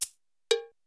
Clickk copy.wav